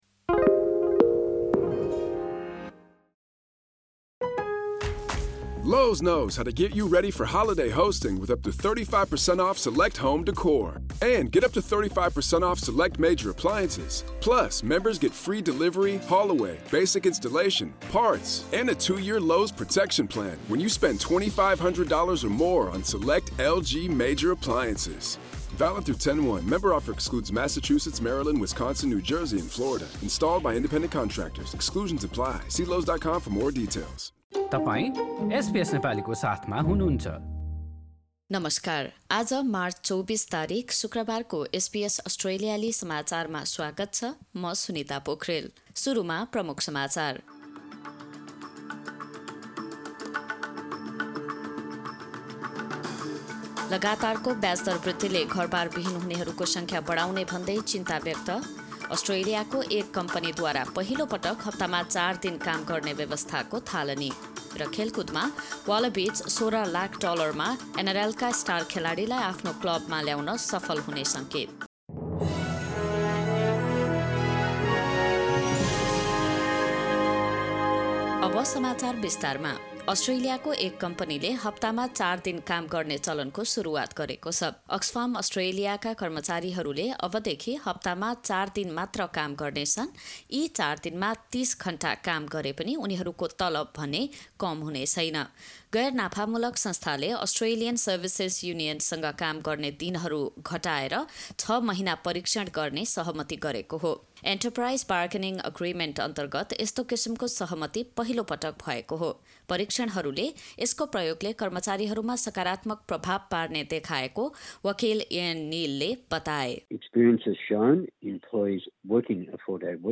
एसबीएस नेपाली अस्ट्रेलिया समाचार: शुक्रवार २४ मार्च २०२३